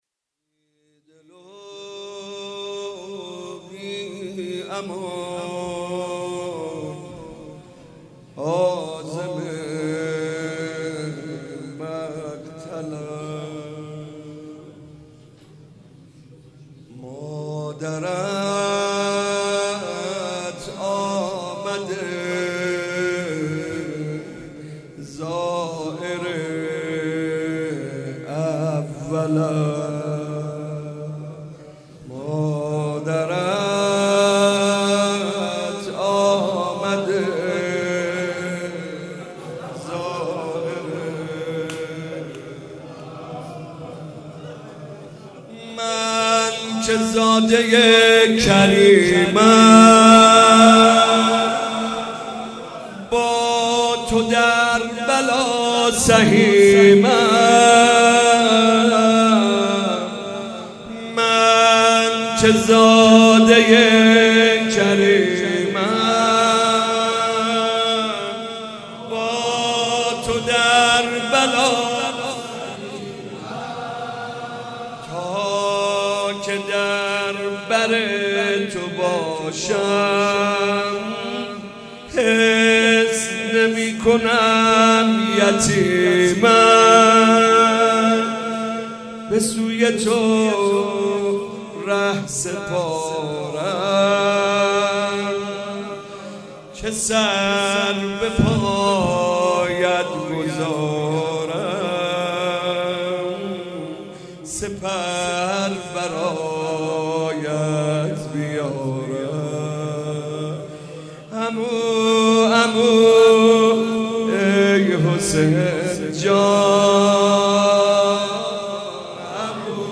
مناسبت : شب پنجم محرم
مداح : محمدرضا طاهری قالب : واحد